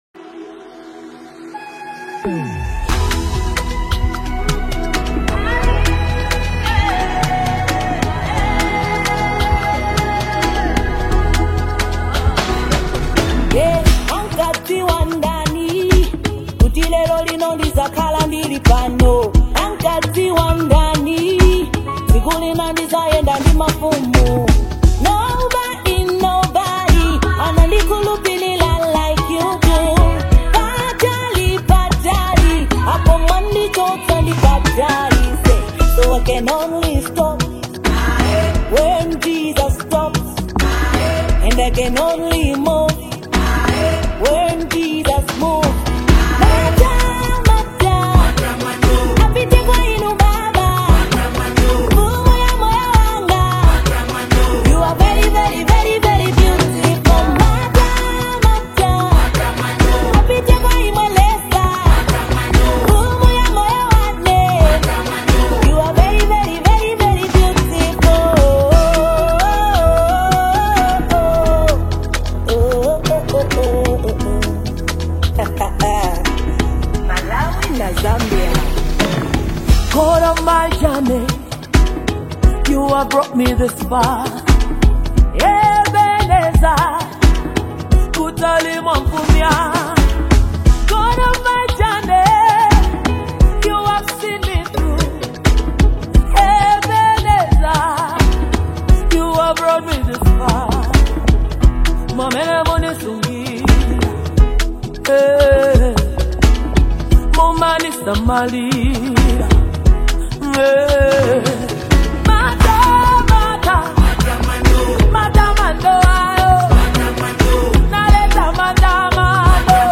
worship song
soft, sincere vocals
rich and soulful delivery